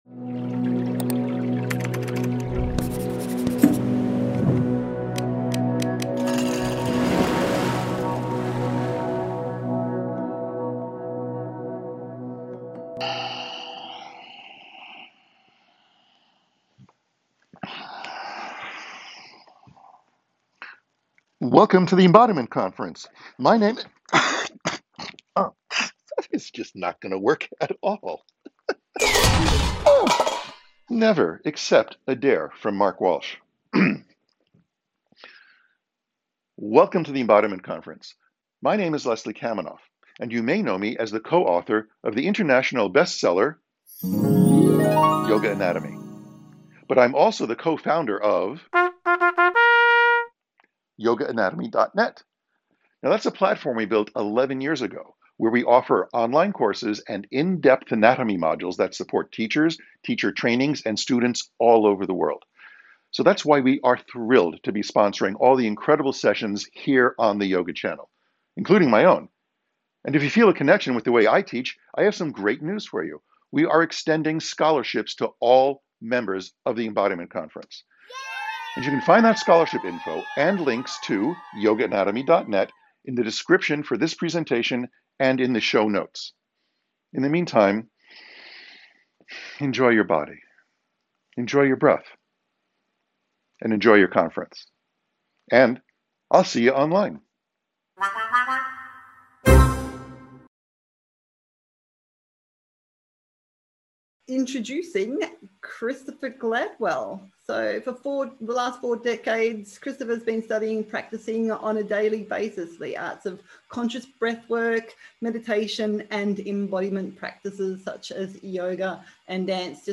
Breath is the key.. Beginning or no understanding of topic, Open to all Some standing/ movement Likely soothing An exploration of the breath through movement, meditation and breath focused practice.
Guided Practices